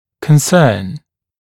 [kən’sɜːn][кэн’сё:н]беспокойство, озабоченность, опасение; затрагивать, касаться, иметь отношение; волновать, беспокоить